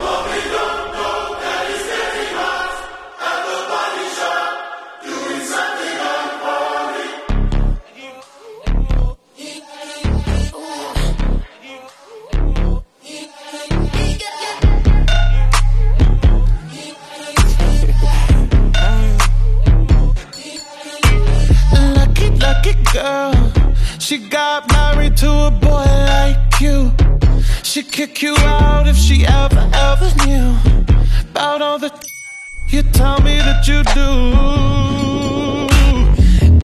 Battle Prime Gameplay # 1 sound effects free download